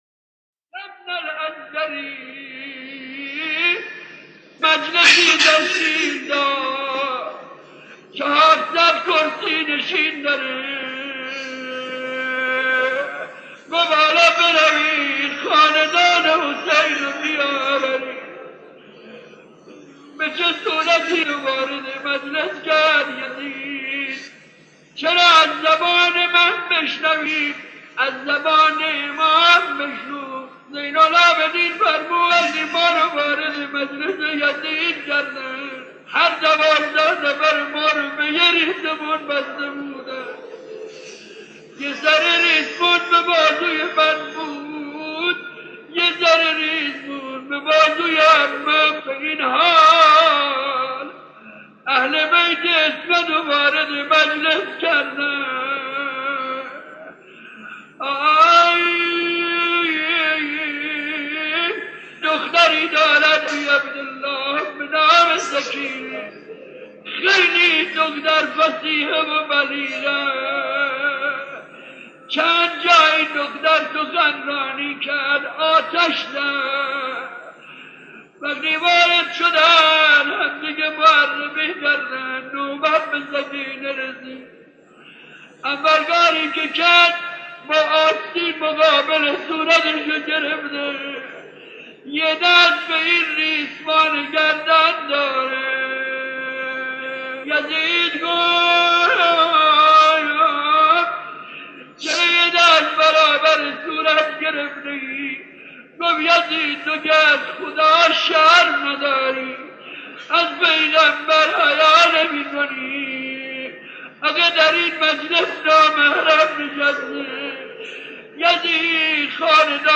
مصیبت‌خوانی درباره رخدادهای بارگاه یزید و مظلومی اسیران کربلا